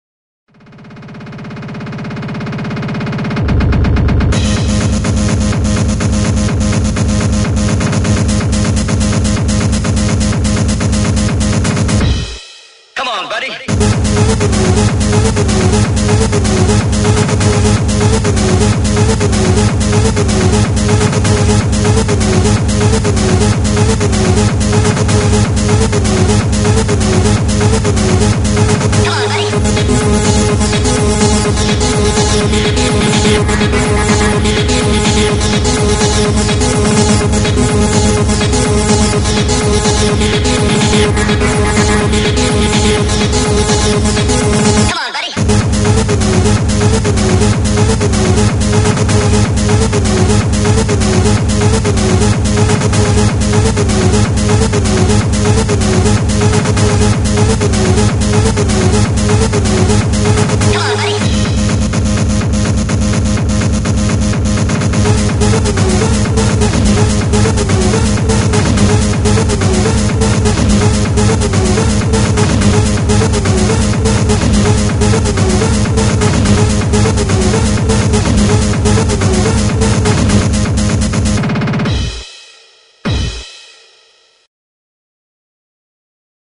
dance/electronic
Hardcore
Techno